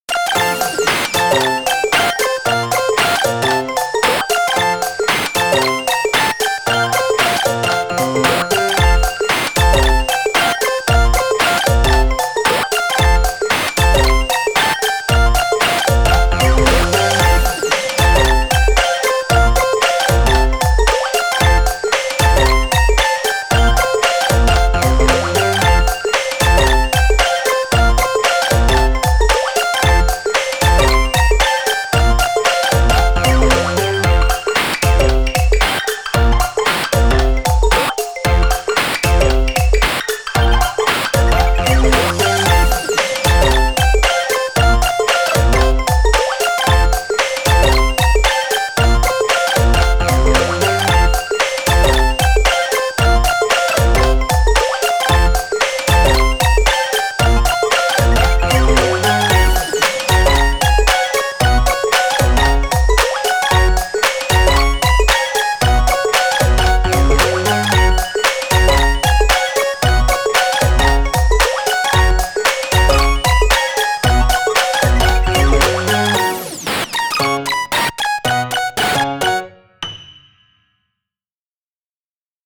チップチューンを意識した、かわいくてピコピコなファミコン風BGMです！